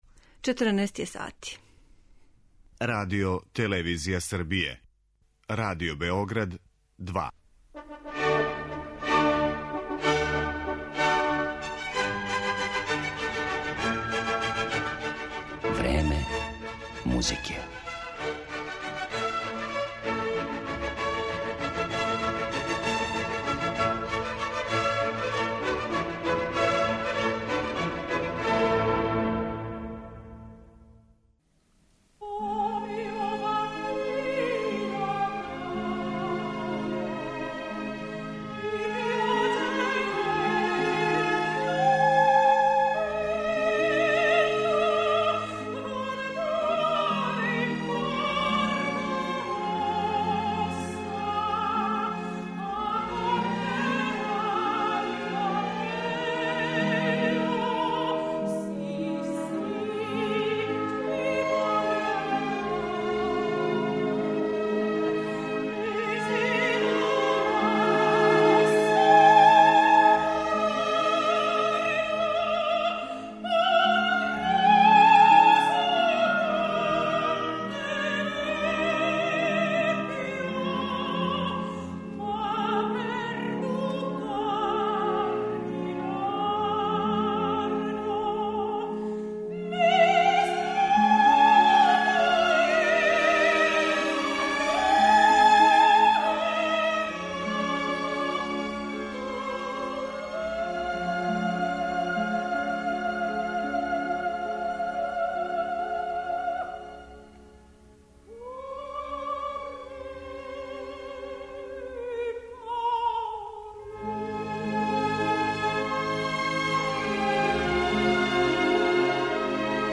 сопран
Управо арије ових трагичних хероина чине део музичког садржаја емисије